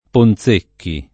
[ pon Z% kki ]